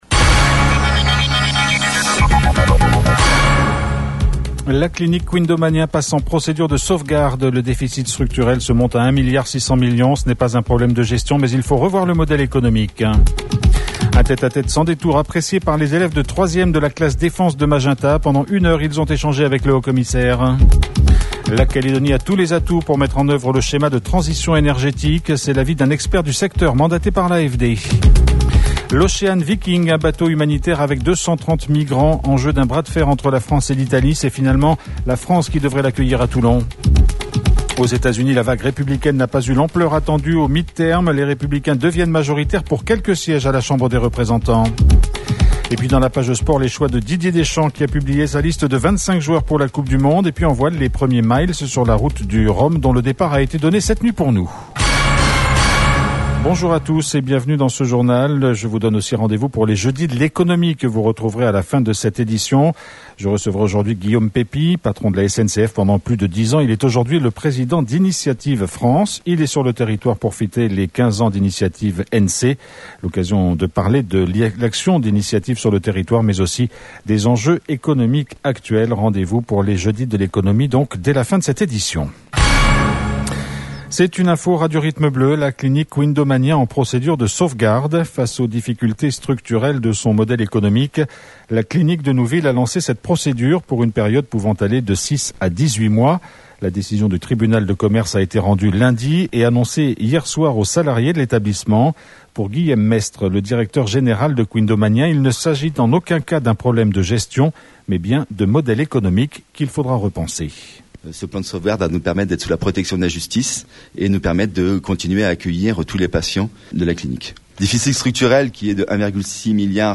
JOURNAL : JEUDI 10 11 22 (MIDI)